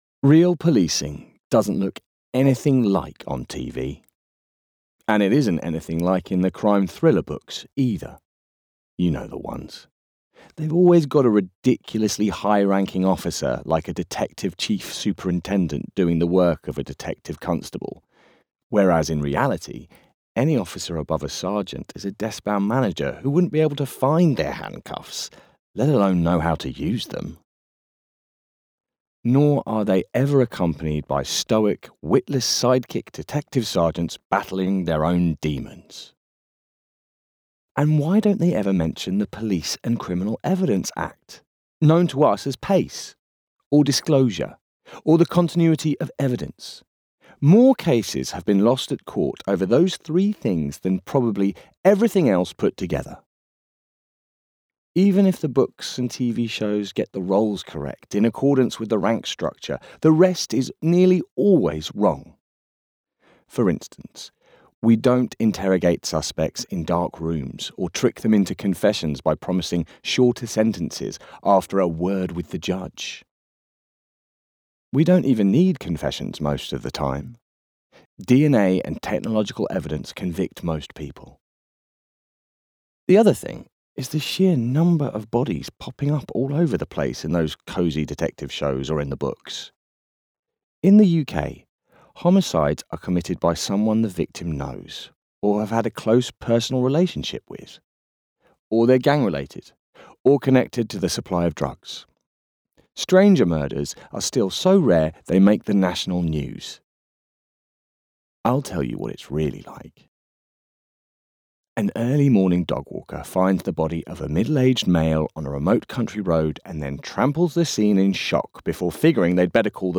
Murder Crime on Gallymay Audio Book Free Sample!
listen to this free sample of the bestselling book, Murder Crime on Gallymay, narrated by Gethin Anthony.